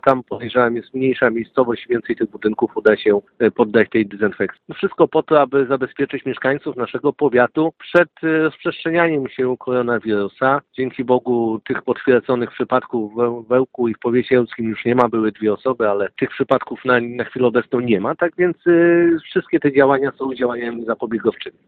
Przedsięwzięciu w naszym regionie patronuje Wojciech Kossakowski – poseł na Sejm z Ełku.
– Dezynfekcja prowadzona była już w Orzyszu, w najbliższym czasie prace wykonywane będą w Białej Piskiej – mówi Kossakowski.